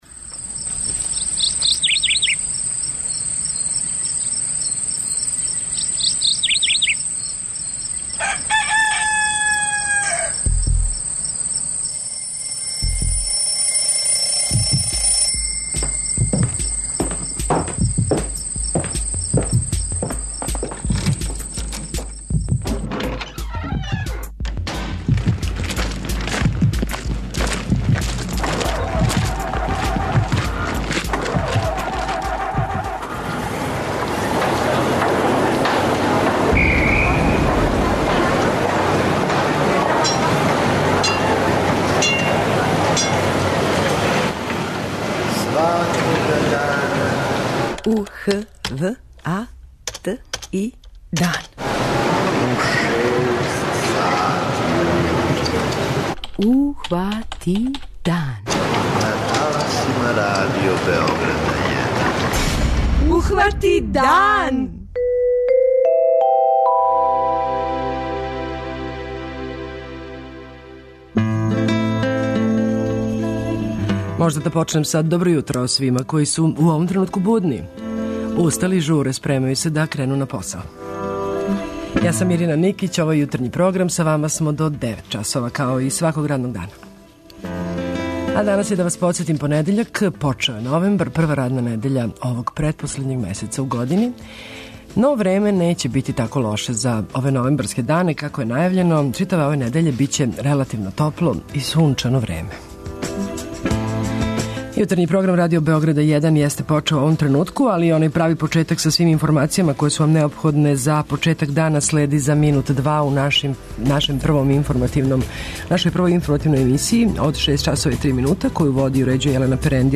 Из садржаја Јутарњег програма издвајамо: